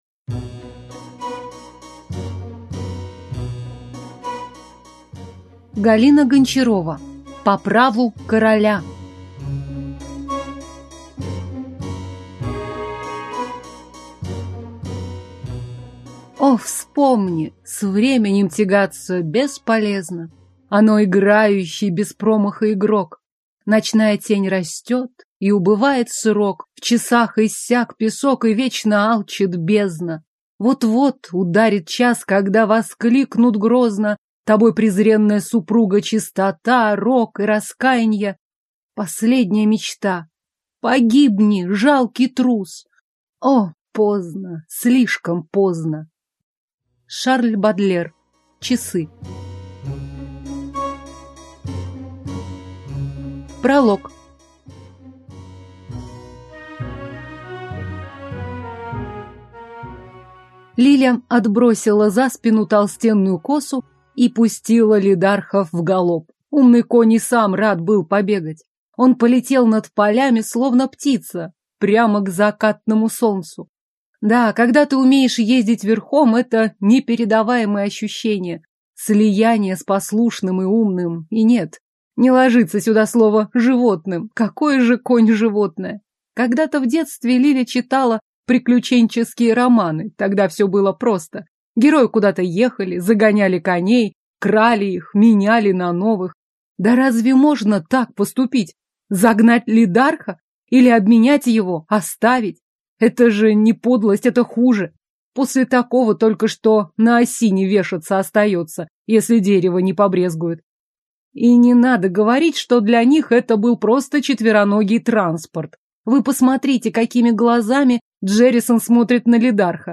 Аудиокнига Средневековая история. По праву короля | Библиотека аудиокниг